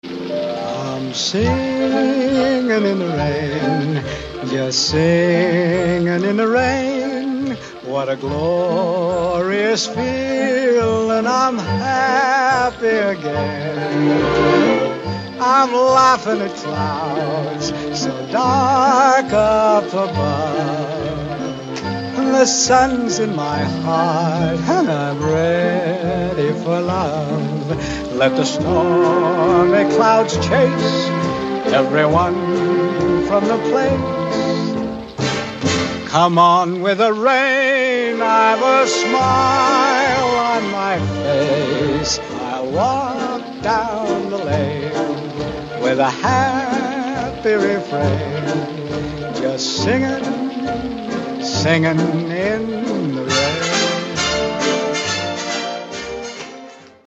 퇴근길에 만난 비 ☂ Rain, sound effects free download
퇴근길에 만난 비 ☂ Rain, softly following me home